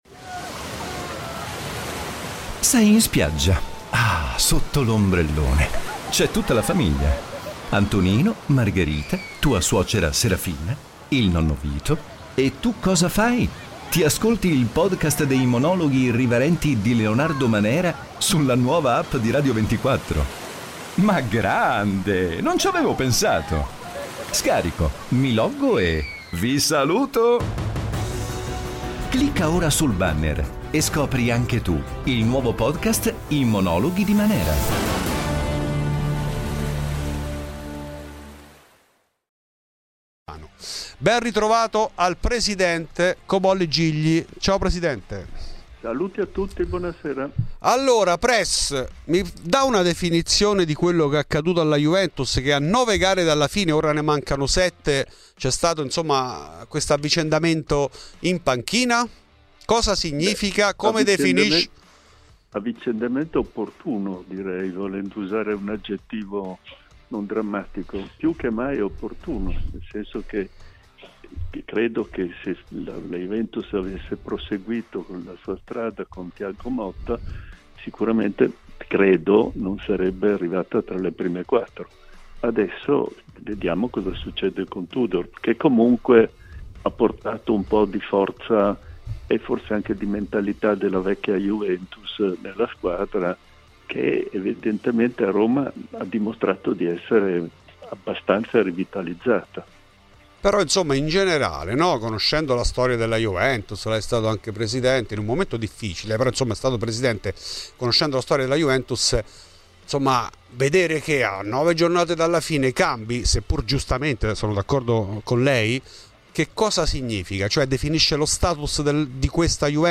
L'intervista integrale nel podcast